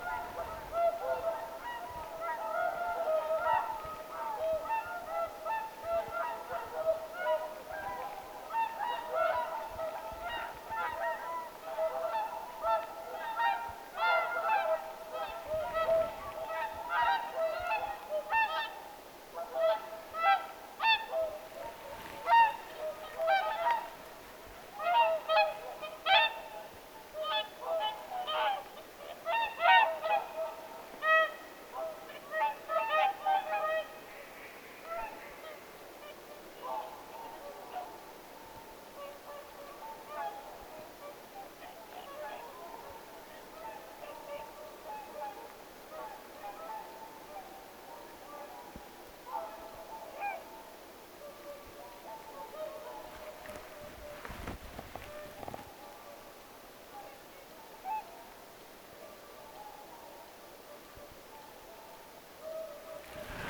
laulujoutsenten
muuttoaura lentää taivaalla
laulujoutsenten_muuttoaura_lentaa_taivaalla_aannellen.mp3